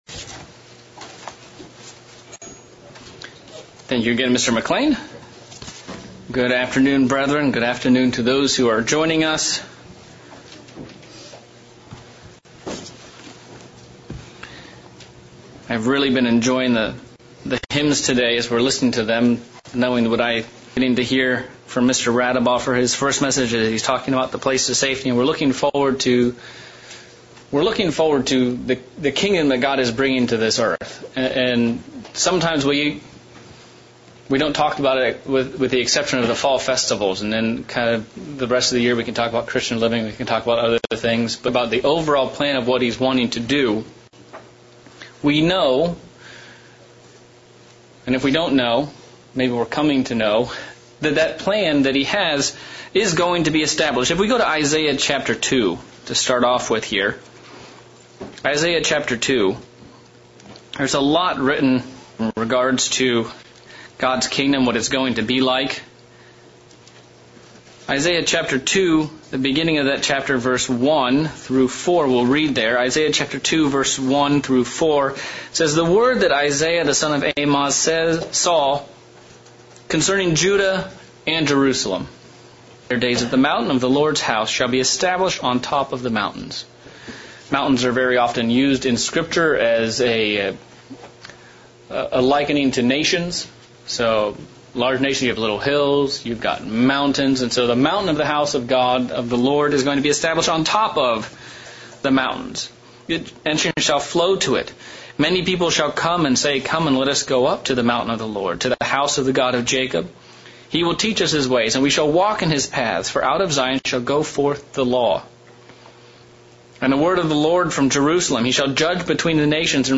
Sermon looking at the subject of national culture and identity. As a Christian what makes a Godly culture?